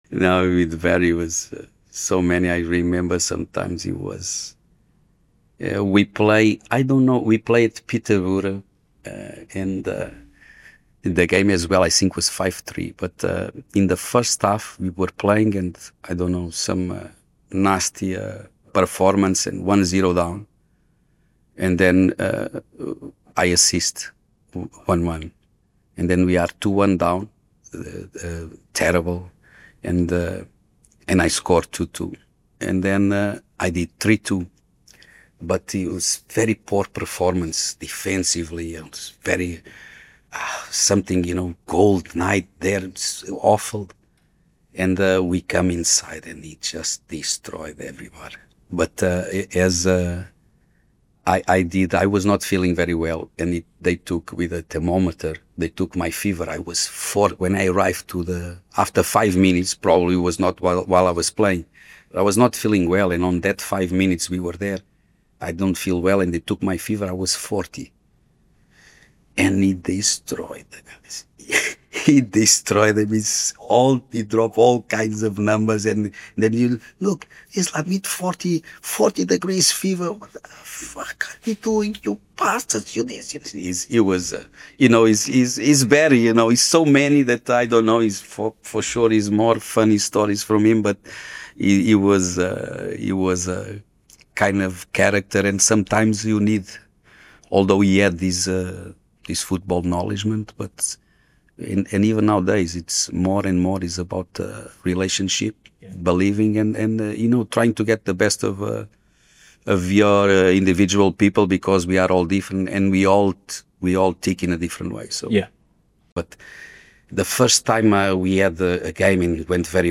In this exclusive full-length interview, the Portuguese winger tells the full story — raw, honest and hilarious.